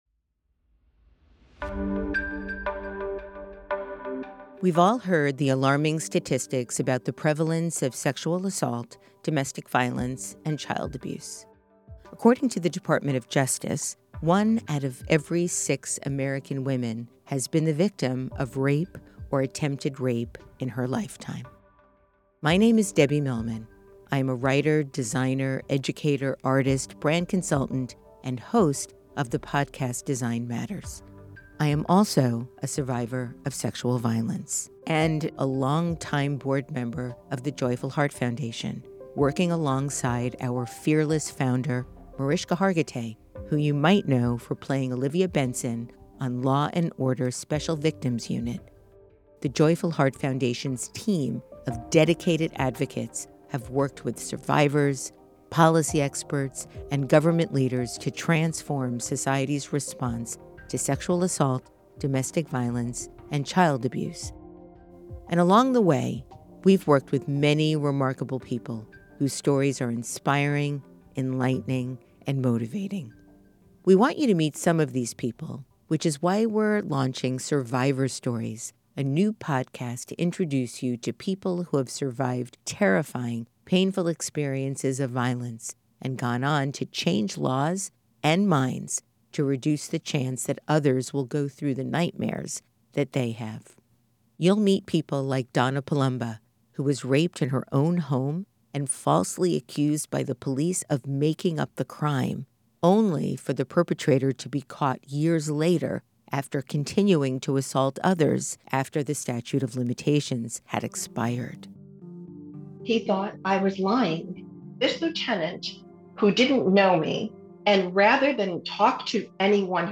Join host Debbie Millman, a survivor and Joyful Heart Foundation board member, as she introduces you to remarkable individuals who experienced trauma yet channeled it into changing laws and minds.